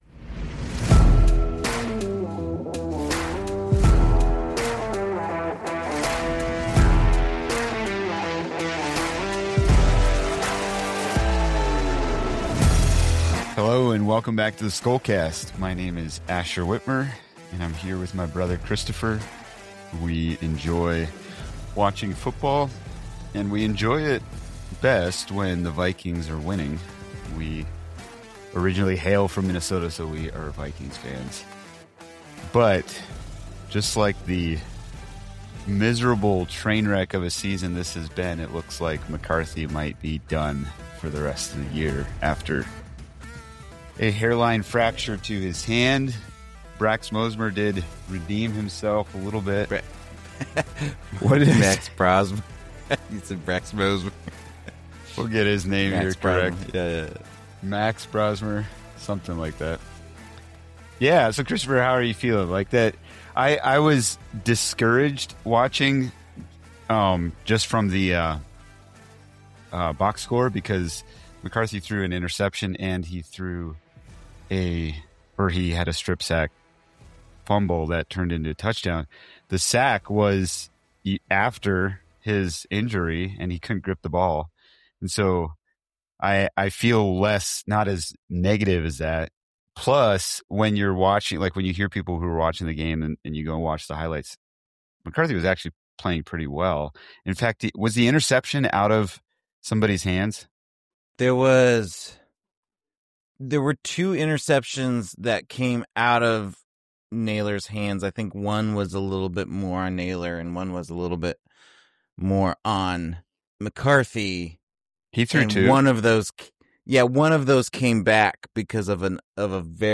A sober, honest conversation for weary Vikings fans.